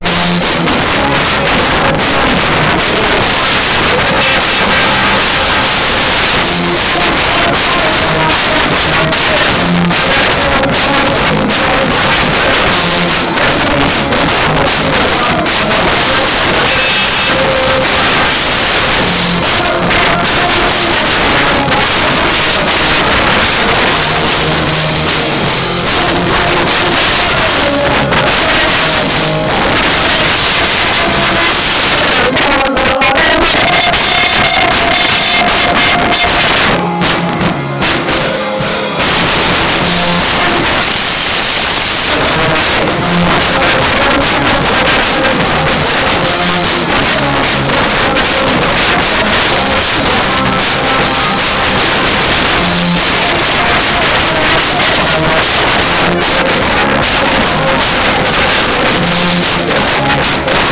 Considering recorded on my phone, do you think they